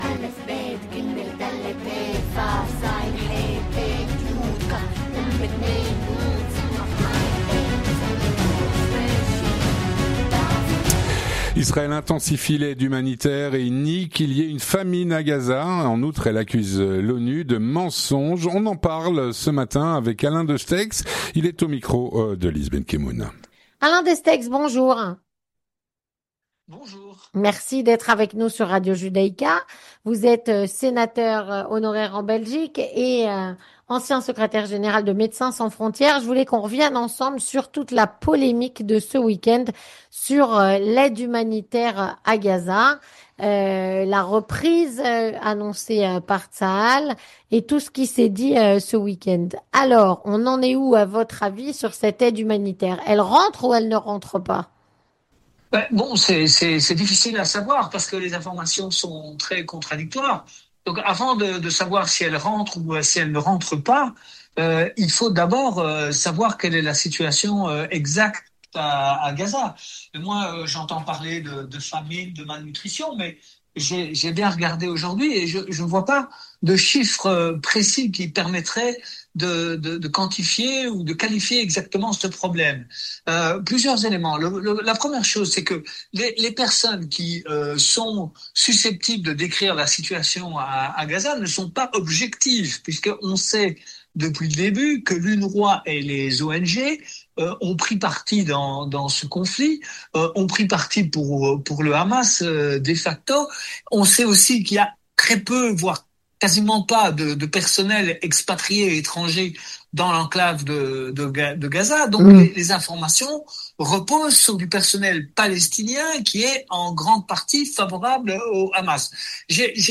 On en parle avec Alain Destexhe, Sénateur honoraire et ancien Secrétaire-Général de "Médecins Sans Frontières".